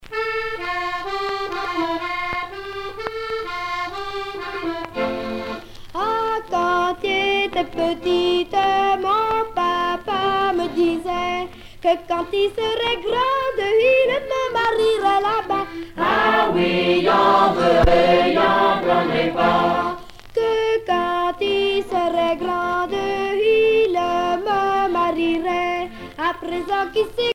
Dialogue mère-fille
Genre laisse
Pièce musicale éditée